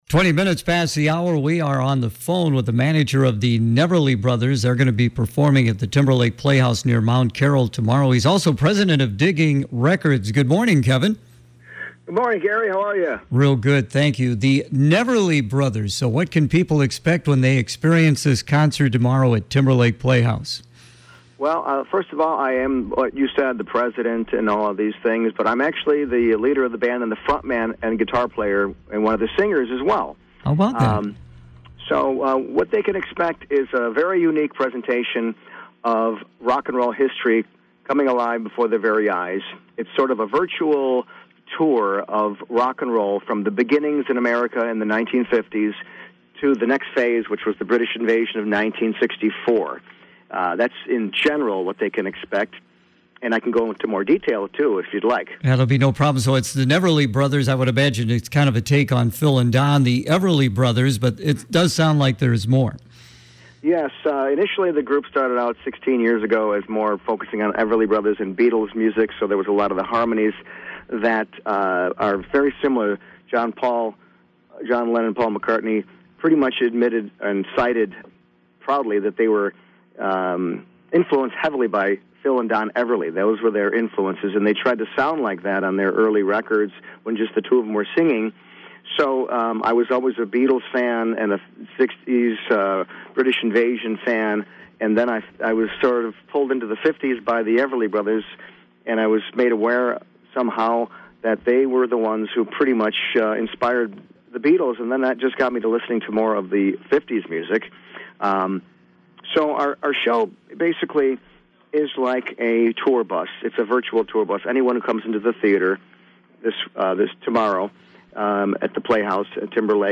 Neverly Brothers to perform at Timberlake Playhouse